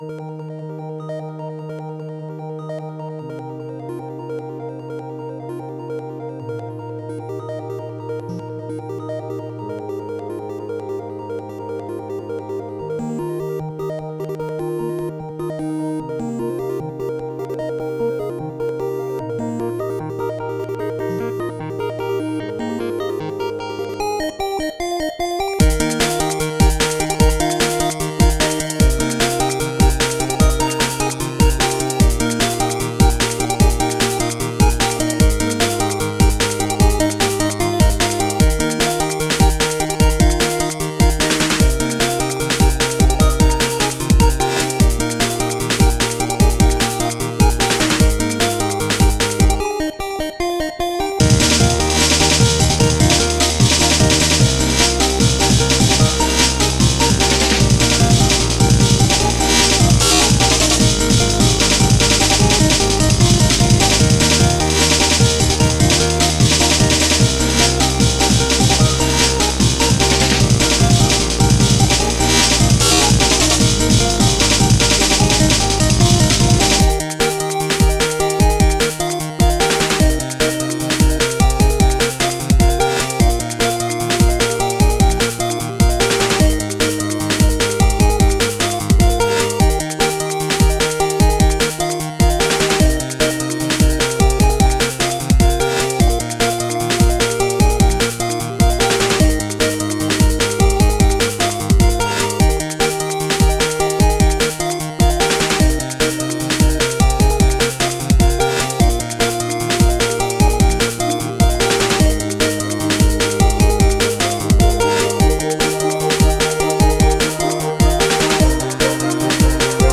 track with a few breaks in it, ignore the violins at the end